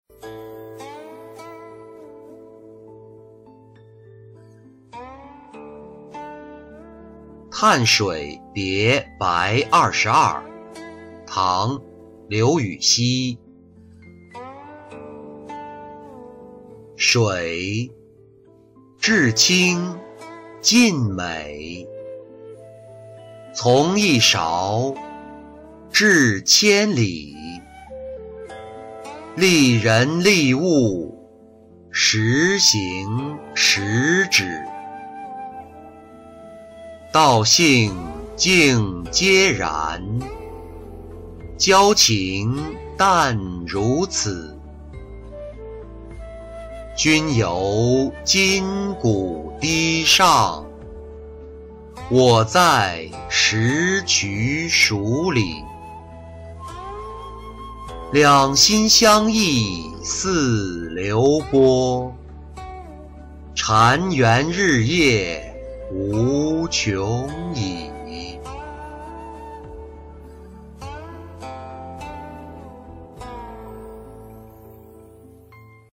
叹水别白二十二-音频朗读